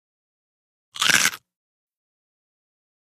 EatCrispySnackSngl PE678003
DINING - KITCHENS & EATING CRISPY SNACK: INT: Single crunch.